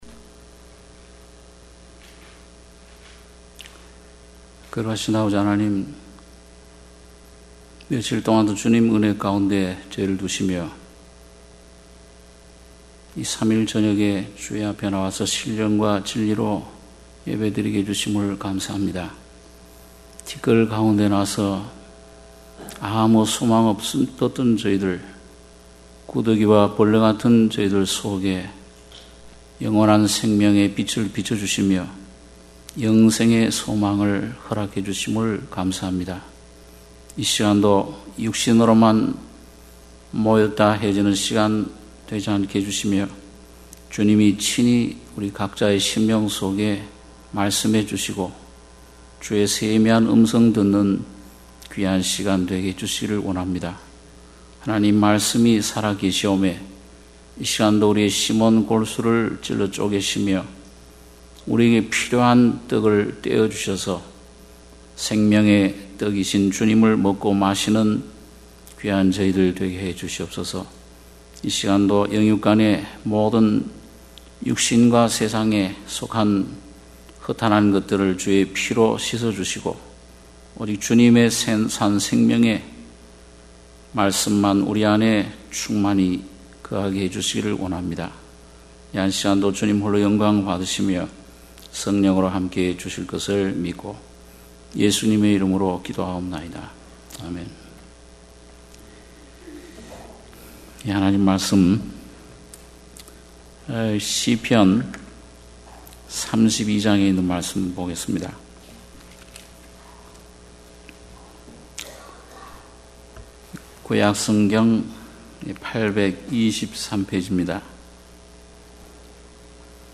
수요예배 - 시편 32장 1-11절